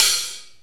NY OPEN HAT.wav